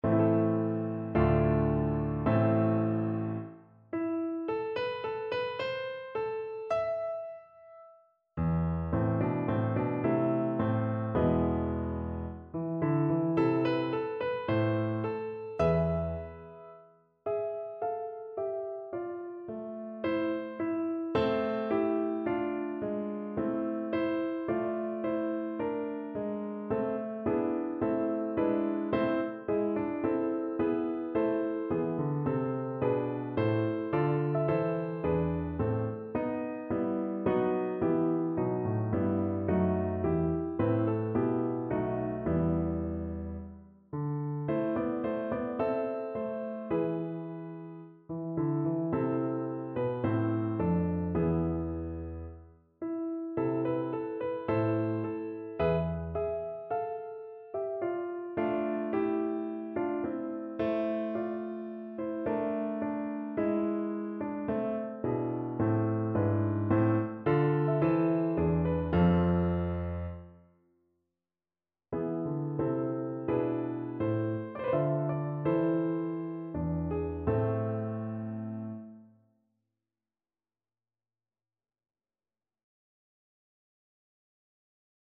Free Sheet music for Piano
No parts available for this pieces as it is for solo piano.
C major (Sounding Pitch) (View more C major Music for Piano )
= 54 Andante risoluto
2/4 (View more 2/4 Music)
Piano  (View more Intermediate Piano Music)
Classical (View more Classical Piano Music)